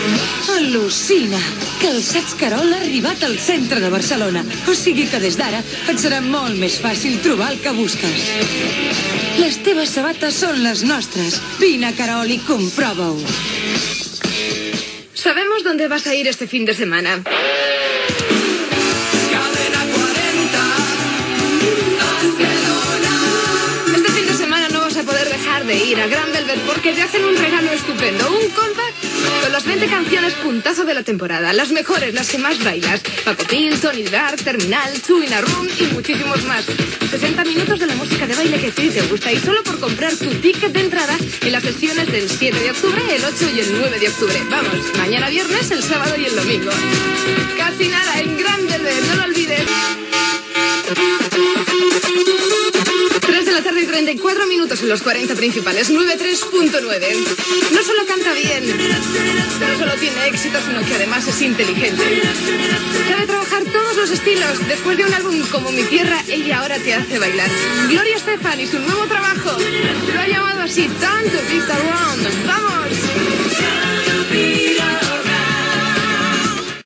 Publicitat, indicatiu de l'emissora, hora i tema musical.
Musical
FM